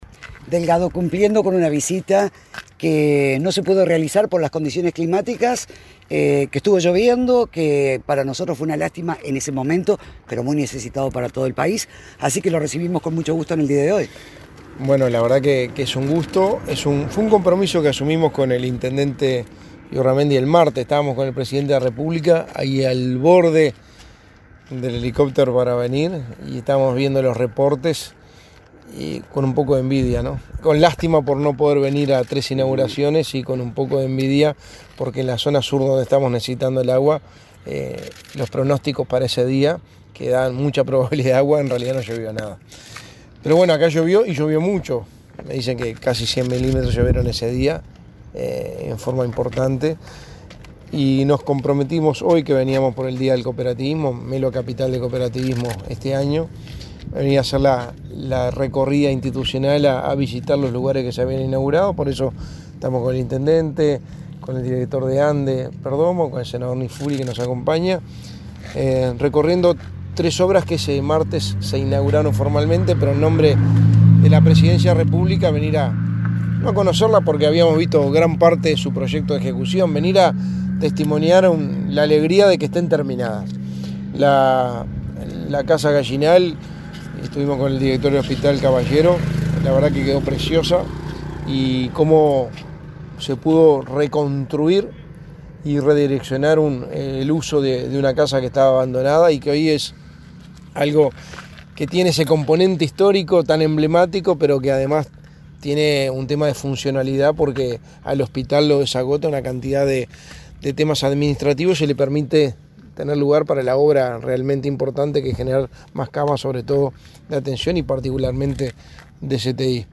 Declaraciones del secretario de Presidencia, Álvaro Delgado
El secretario de la Presidencia de la República, Álvaro Delgado, fue entrevistado por medios informativos en Melo, luego de recorrer la recientemente